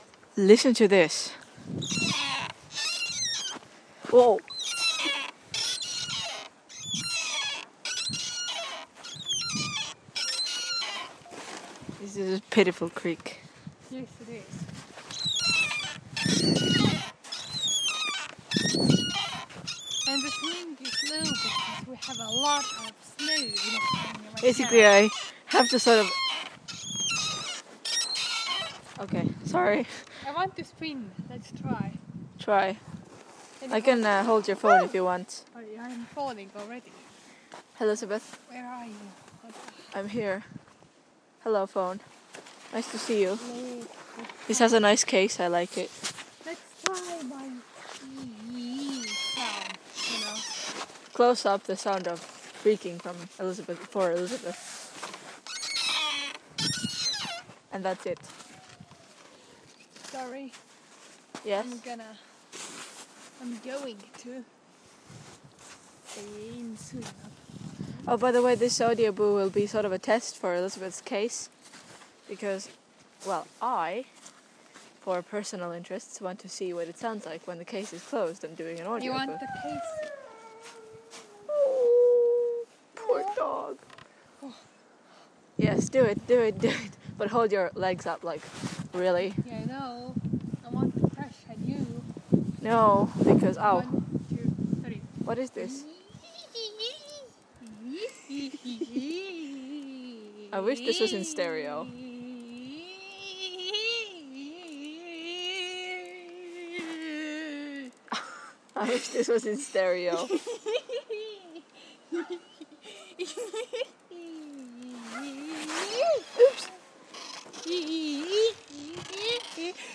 Very creaky swing